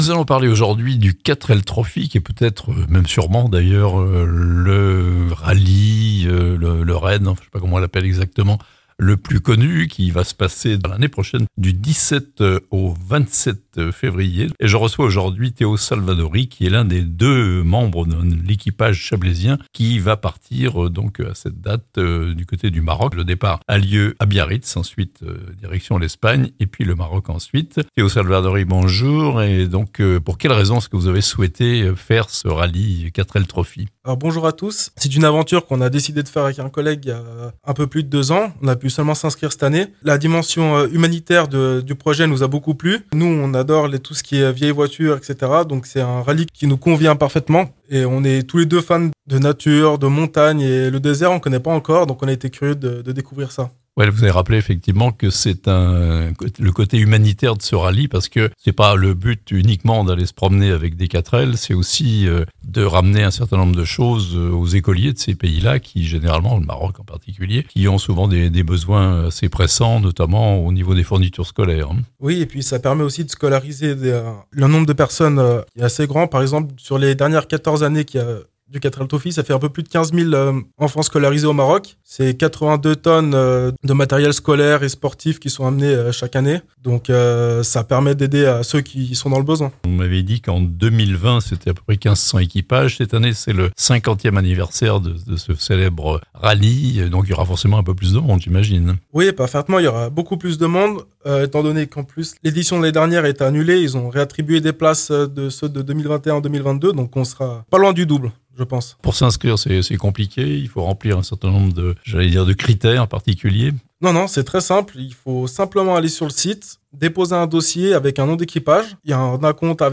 2 chablaisiens au départ du prochain 4L Trophy (interview)